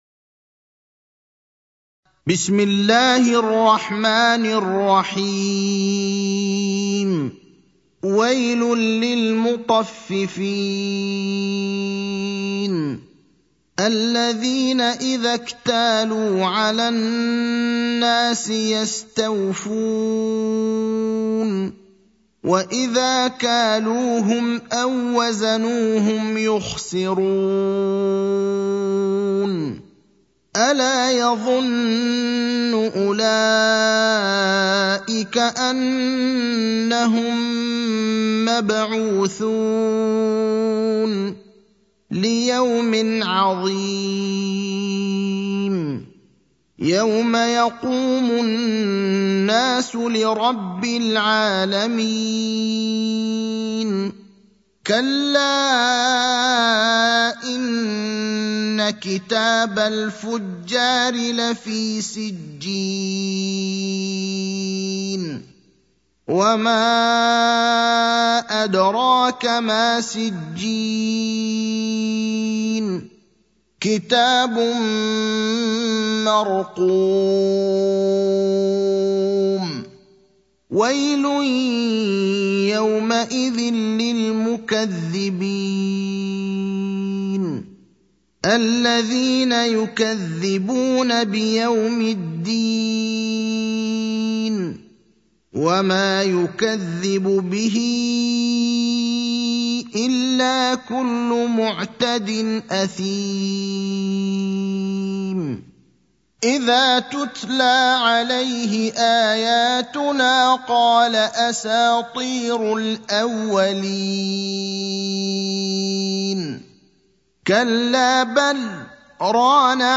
المكان: المسجد النبوي الشيخ: فضيلة الشيخ إبراهيم الأخضر فضيلة الشيخ إبراهيم الأخضر المطففين (83)